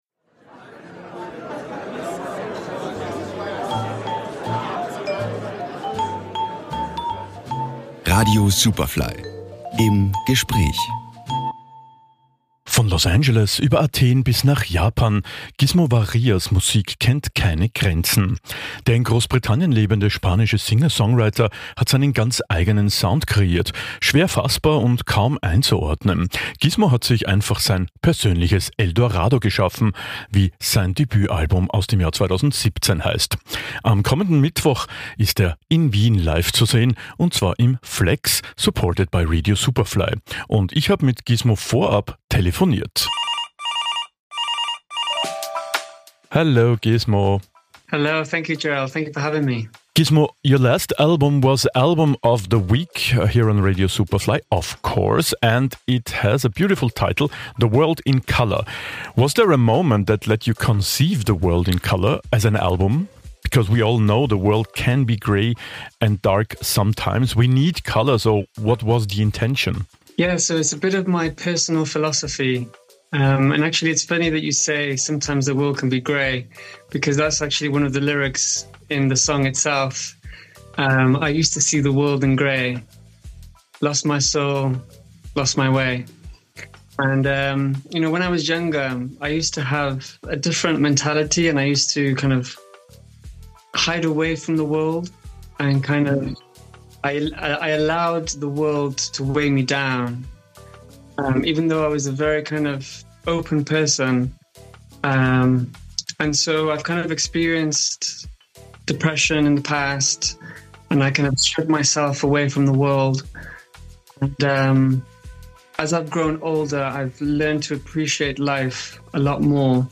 Superfly im Gespräch